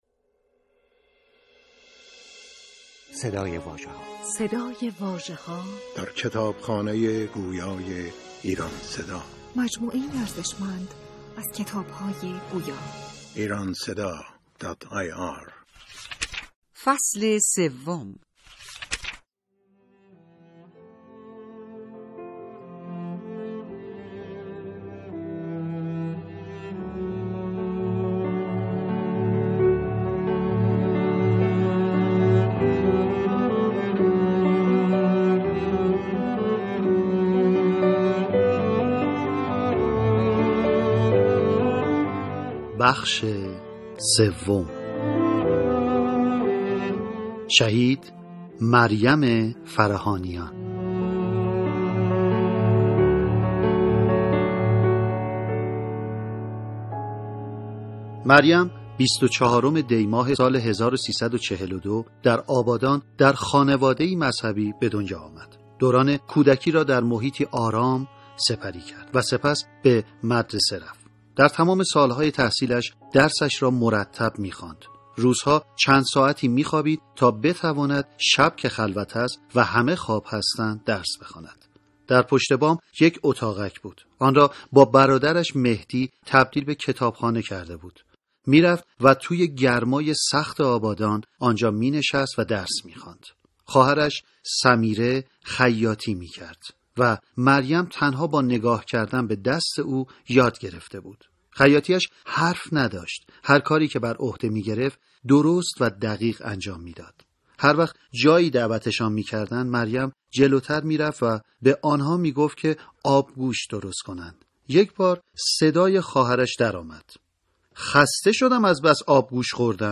زندگی‌نامه‌‌ی ۲۲ بانوی شهید دوران انقلاب اسلامی و دفاع‌مقدس، به همت «علی رستمی» در کتاب «چشمه‌های یاس» تدوین شده و در قالب کتاب گویا درآمده است.